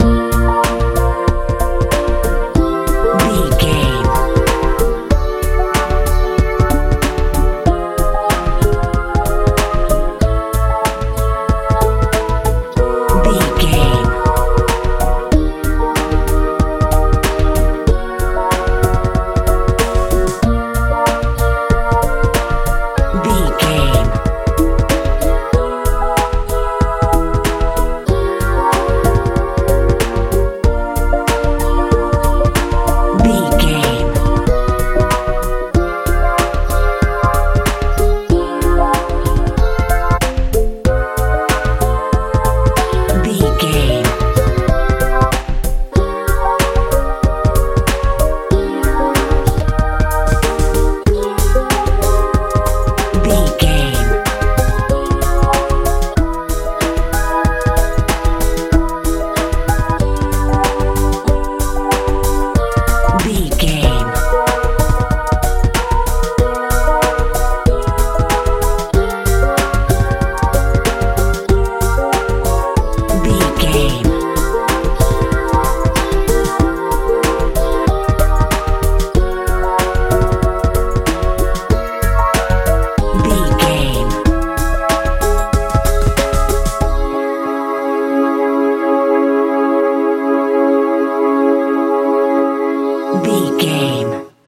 modern pop feel
Ionian/Major
B♭
light
bass guitar
drums
synthesiser
80s
90s
strange
suspense